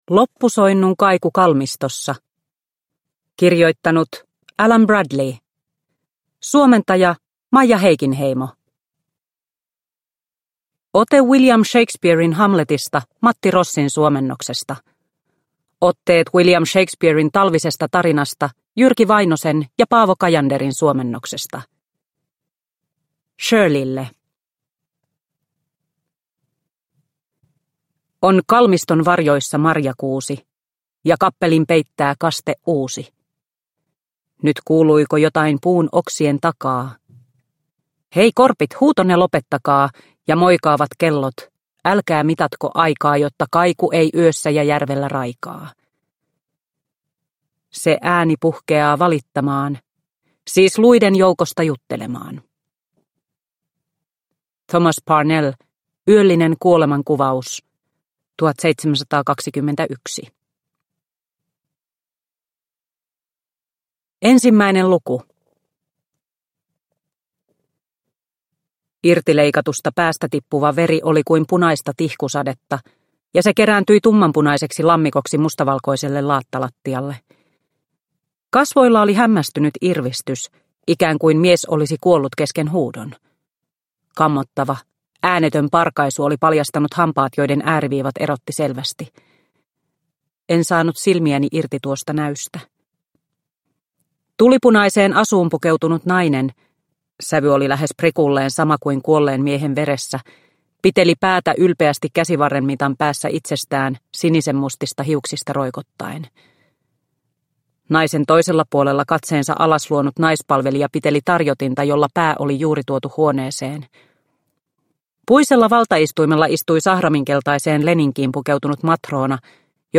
Loppusoinnun kaiku kalmistossa – Ljudbok – Laddas ner